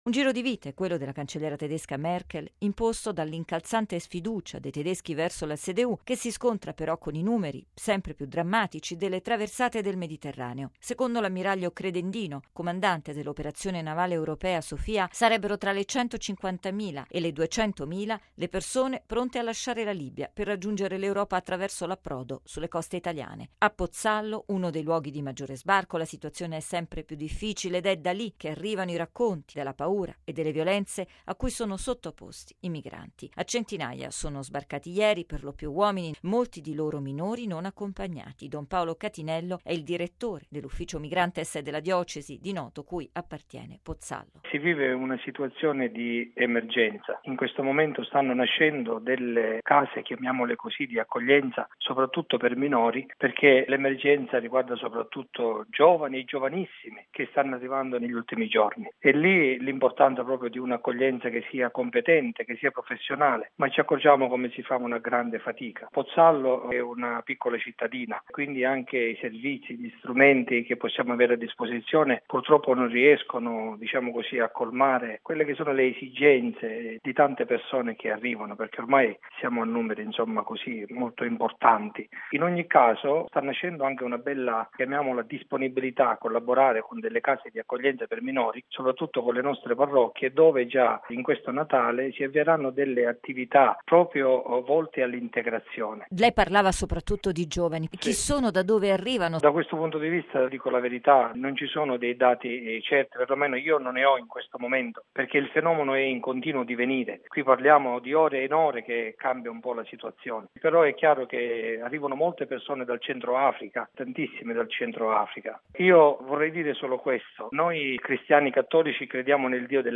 Bollettino Radiogiornale del 06/12/2016